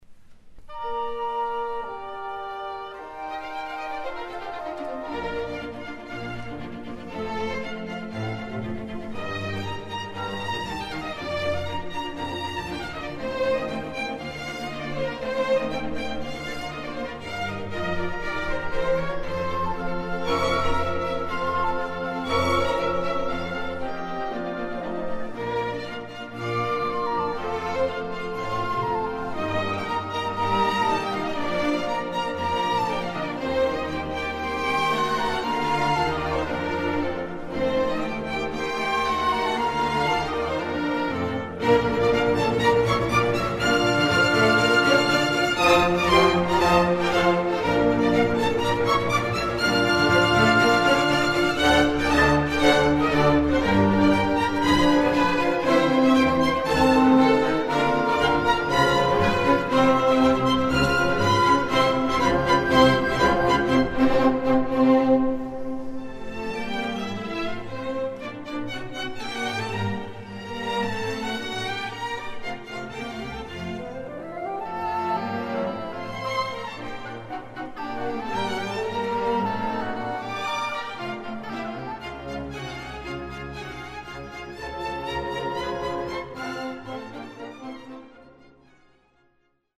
Sinfonieorchester TonkünstlerEnsemble Wien/Lilienfeld